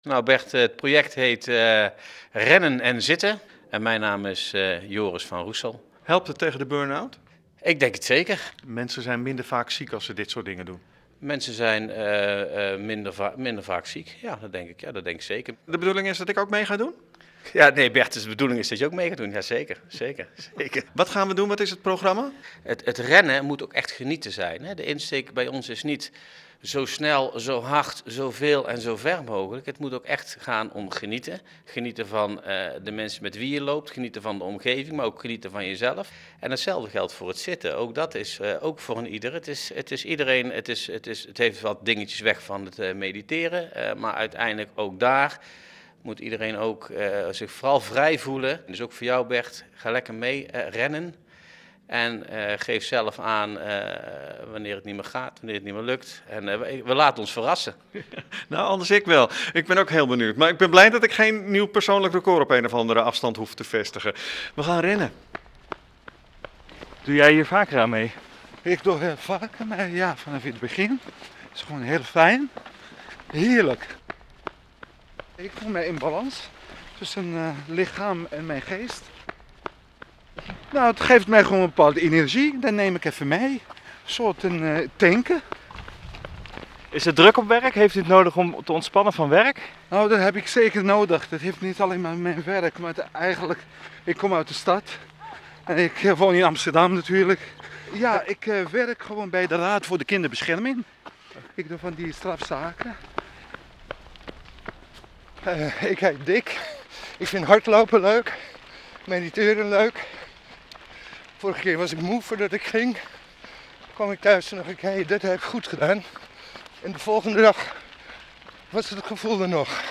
RJNO Rennenenzitten.mp3 Fragment Radio1-journaal …